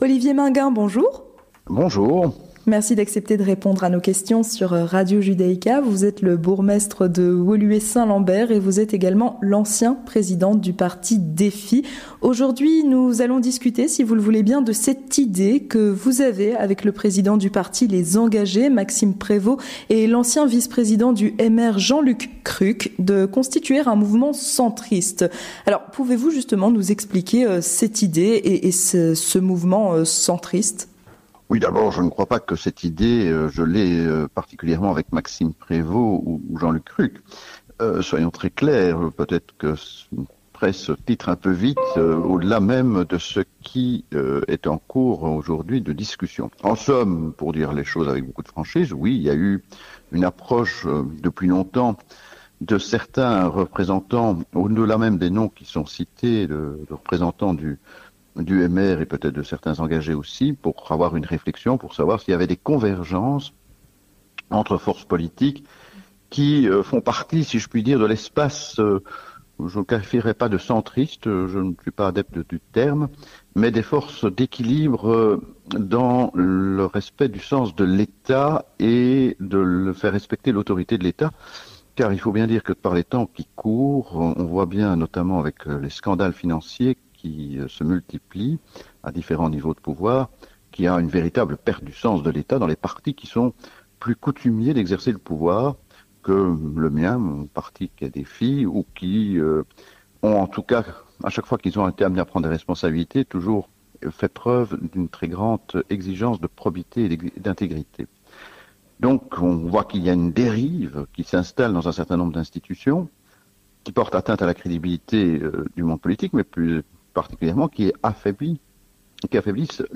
L'Entretien du Grand Journal avec Olivier Maingain (DéFI) - bourgmestre de Woluwe Saint Lambert